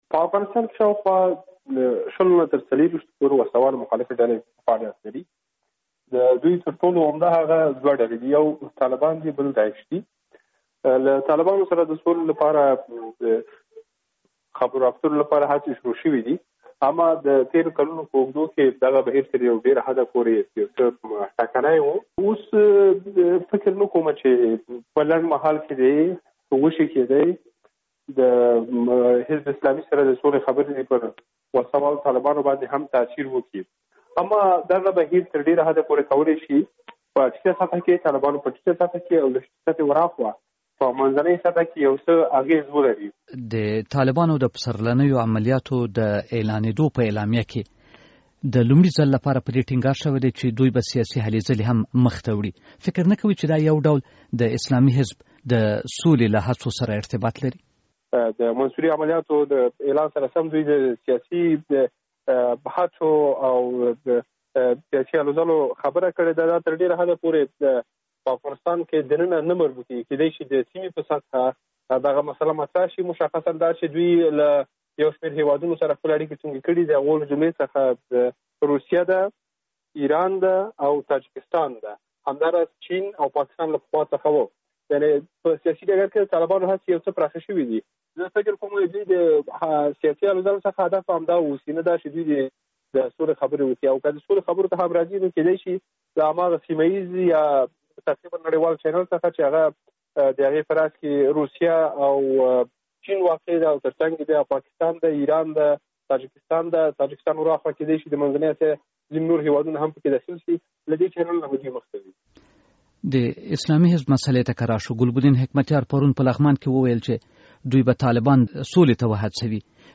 مرکه.